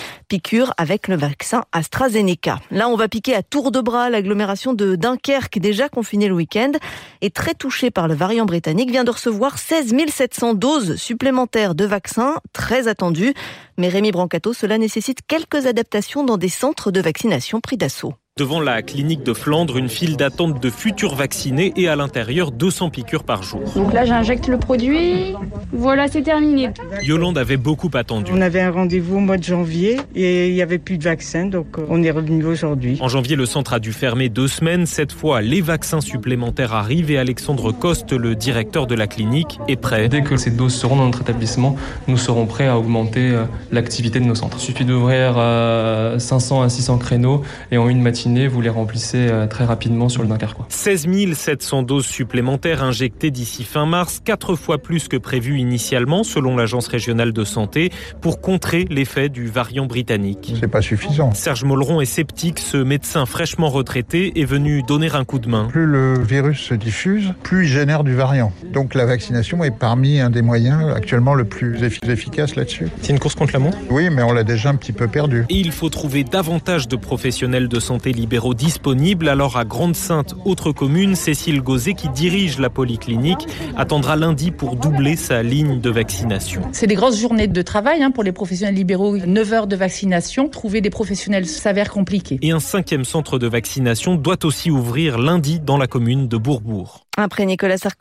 Vaccination : Interview